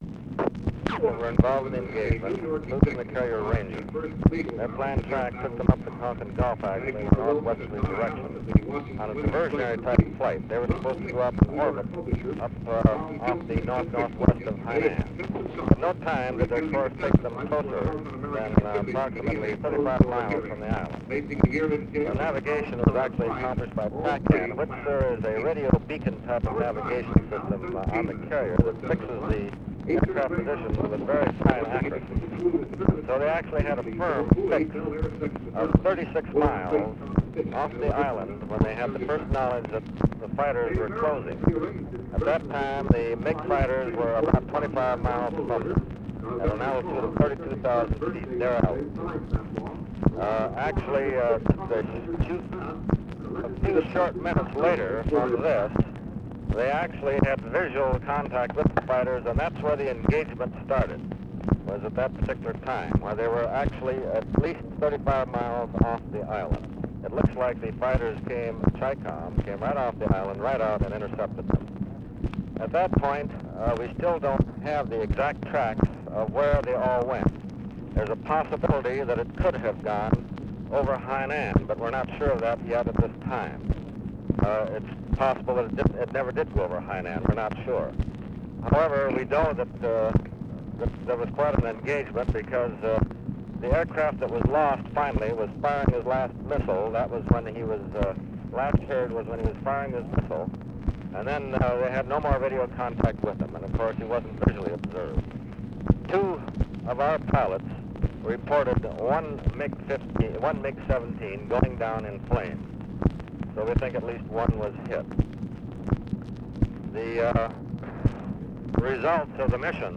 Conversation with PENTAGON COMMAND CENTER, April 9, 1965
Secret White House Tapes